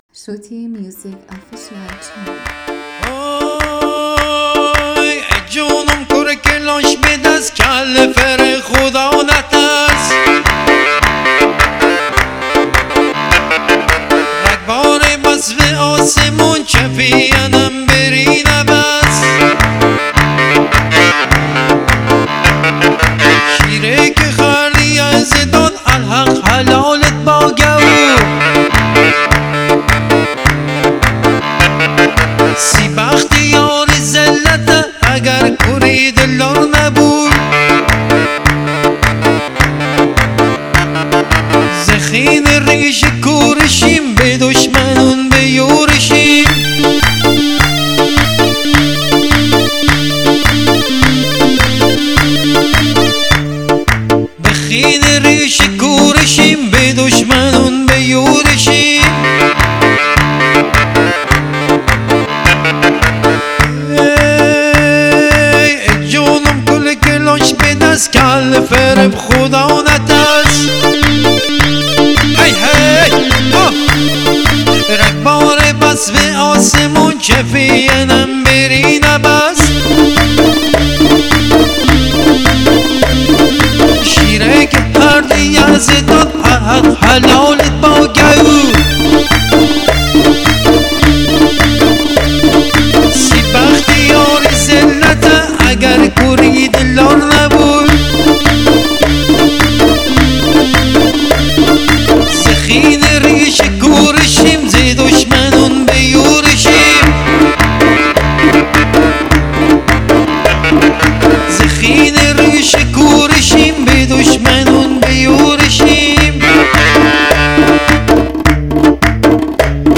11 ژانویه 2024 دانلود ریمیکس